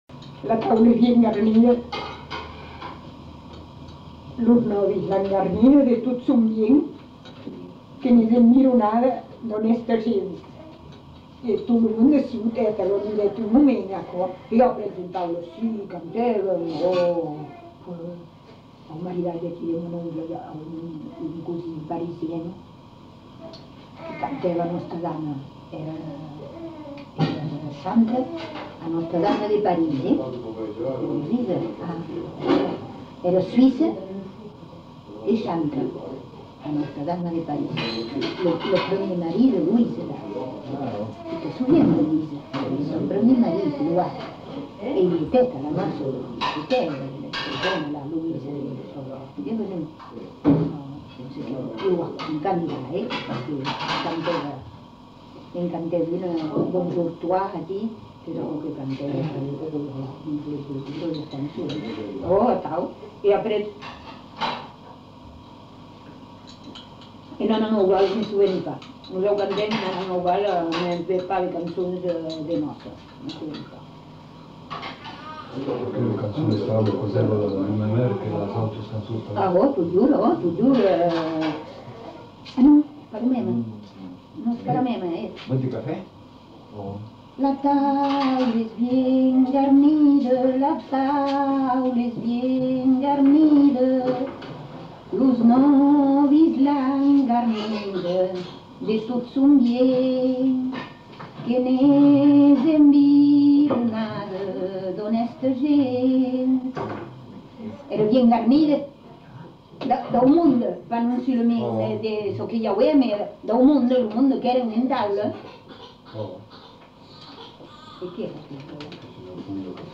Aire culturelle : Marmandais gascon
Genre : chant
Effectif : 1
Type de voix : voix de femme
Production du son : chanté